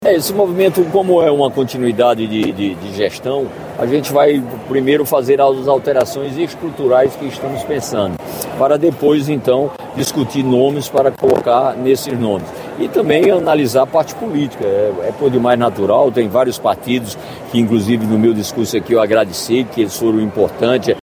Os comentários de Cícero Lucena foram registrados pelo programa Correio Debate, da 98 FM, de João Pessoa, nesta quinta-feira (02/01).